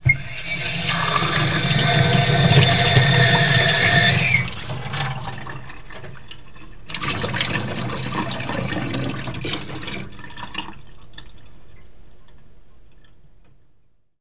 HOUSEHOLD SOUND EFFECTS
Tap at a Sink
TAP-SINK.WAV